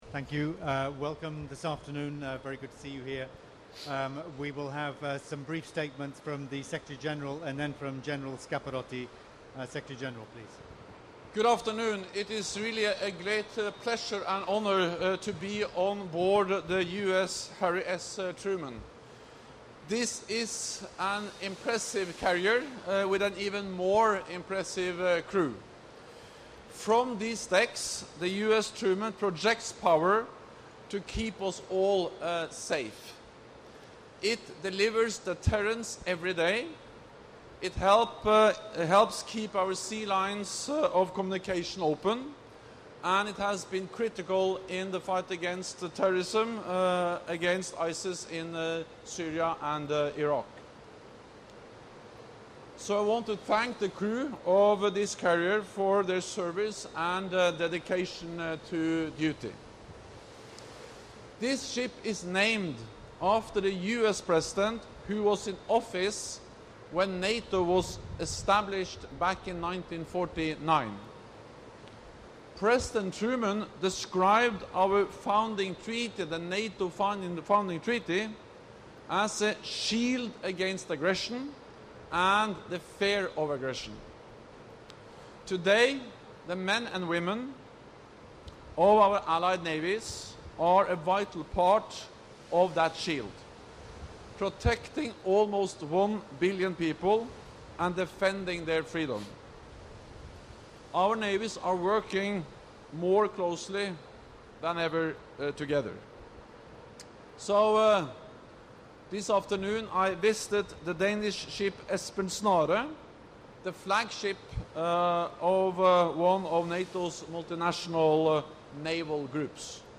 NATO Secretary General Jens Stoltenberg visited the aircraft carrier USS Harry S. Truman in the North Sea on Friday (12 October 2018), together with the Supreme Allied Commander Europe, General Curtis Scaparrotti. The USS Truman is en route to participate in NATO’s biggest exercise in years, Trident Juncture 2018, joining around sixty other ships from across the Alliance.